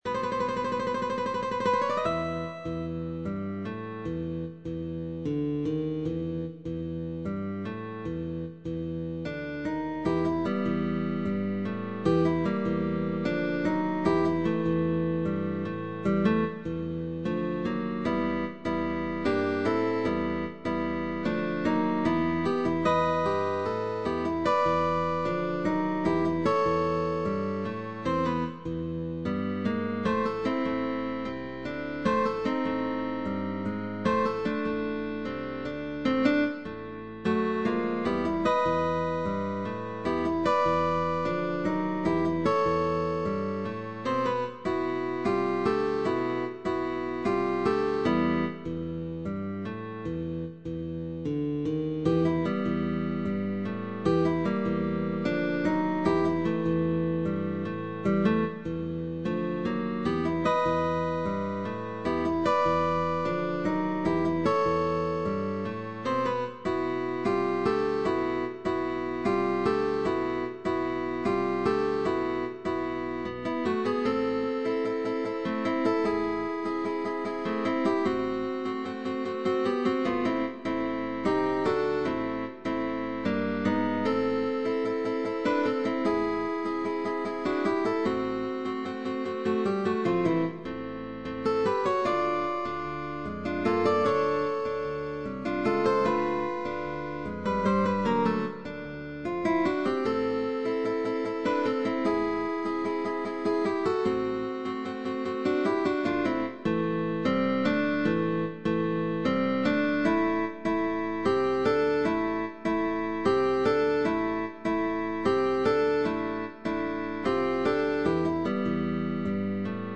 CUARTETO DE GUITARRAS